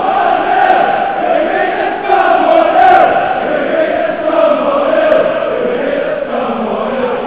One-nil.wav 1-0, we beat the Scum 1-0, etc (1993 FA Cup Semi). 57kB